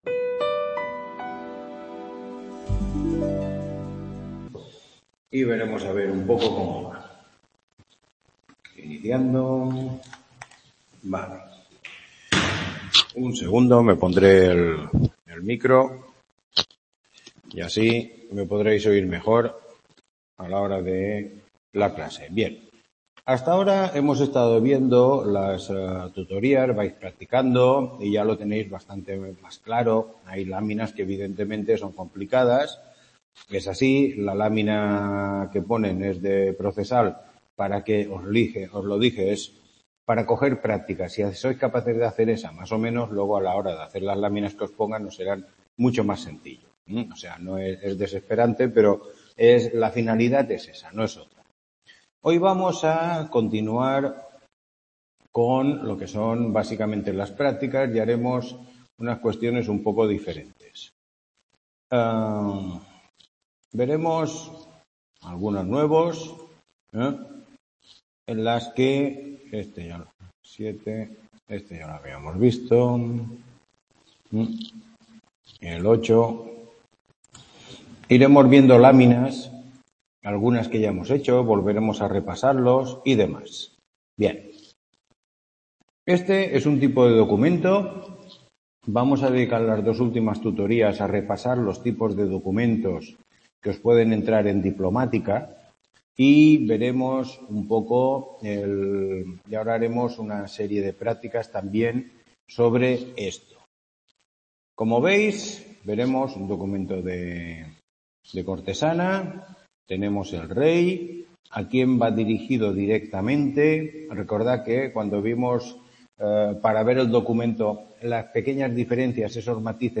Tutoría 9